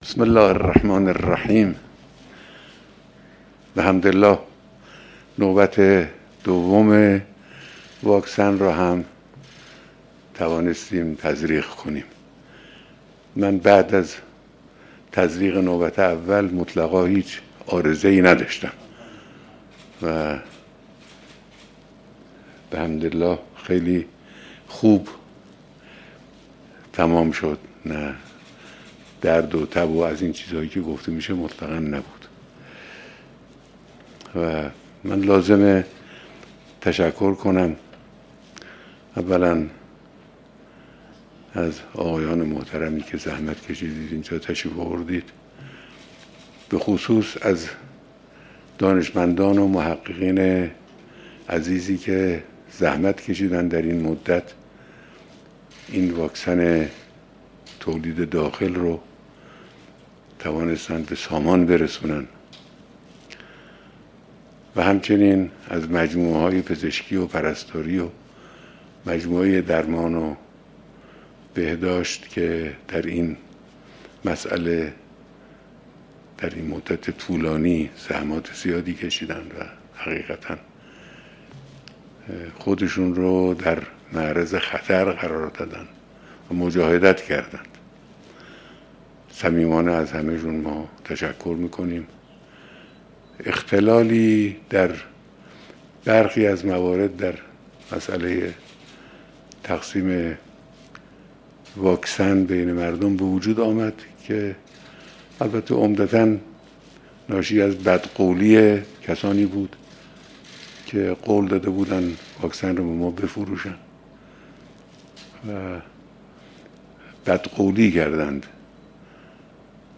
سخنان رهبر انقلاب اسلامی پس از دریافت نوبت دوم واکسن کوو ایران برکت
بیانات پس از دریافت نوبت دوم واکسن کوو ایران برکت